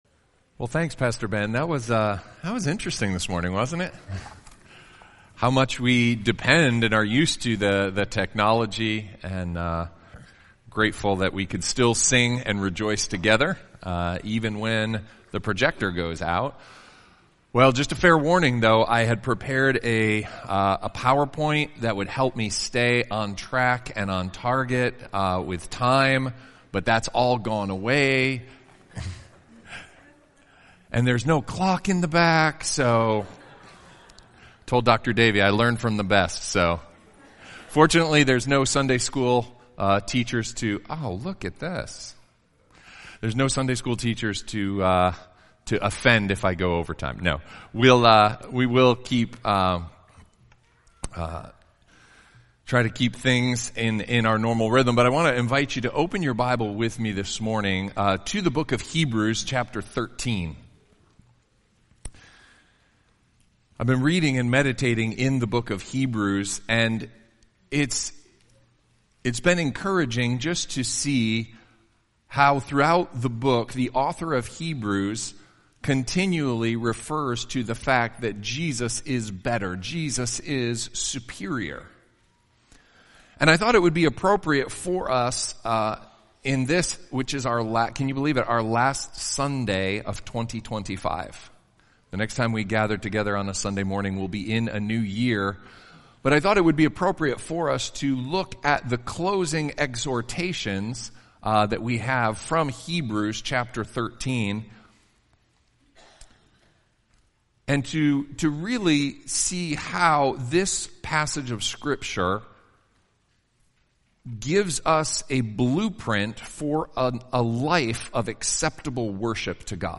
Colonial Baptist Church Sermons